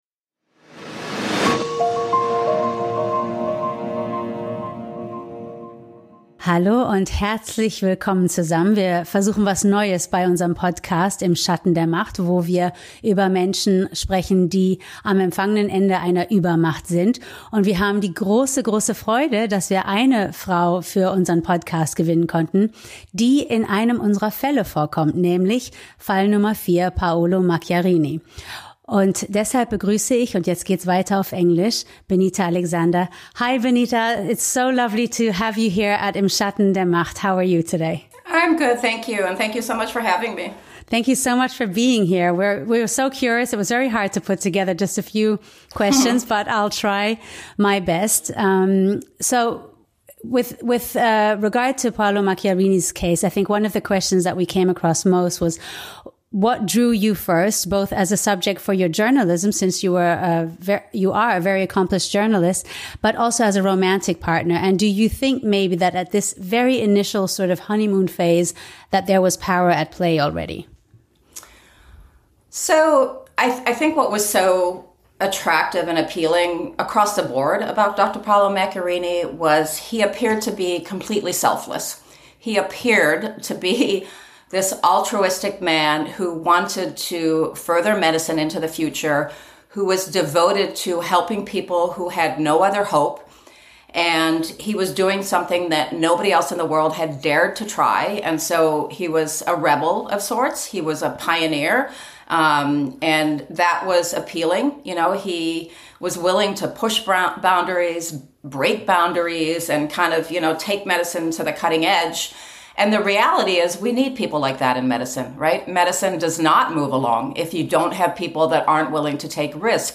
Wir hatten das große Glück, die Frau, die im Zentrum seiner Lügen stand, zu sprechen.